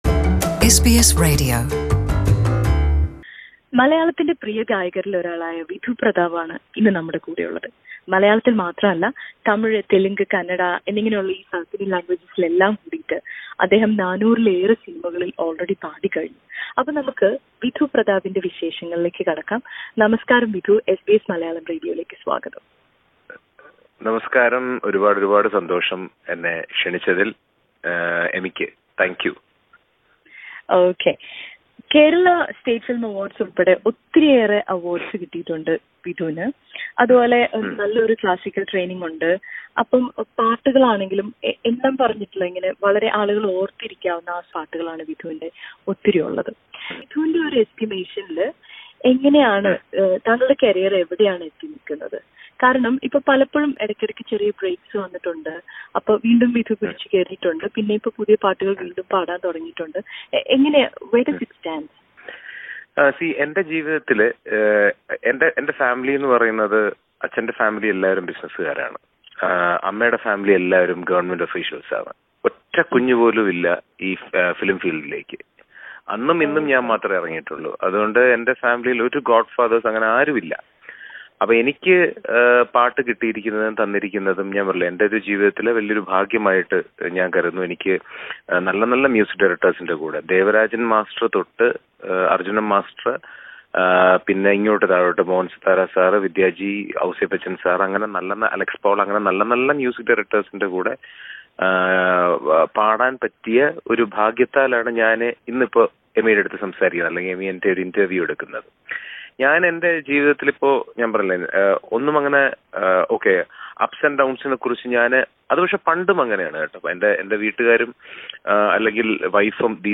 During his recent visit to Australia, playback singer Vidhu Prathap speaks to SBS Malayalam. Listen to the interview.